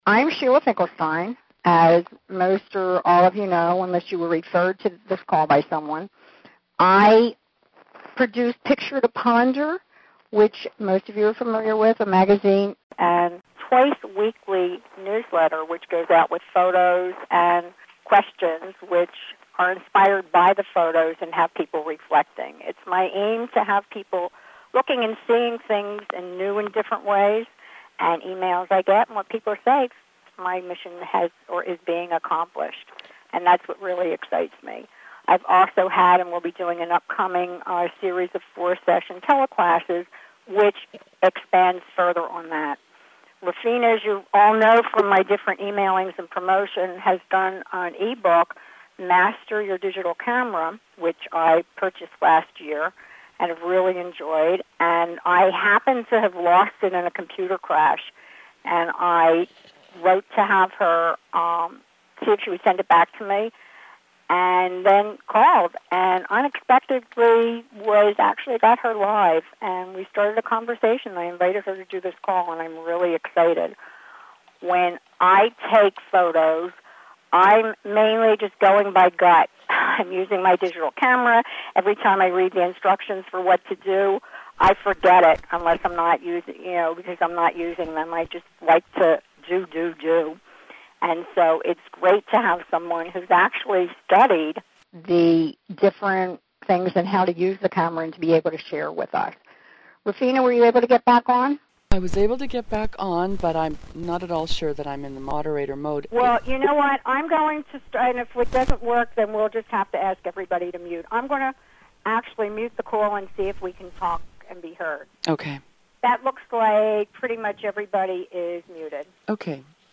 The Teleclass INTERVIEW